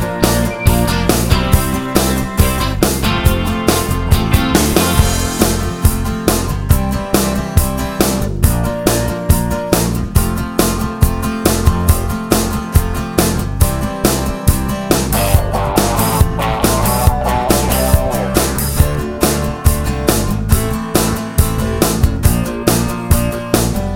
No Piano Pop (1970s) 4:22 Buy £1.50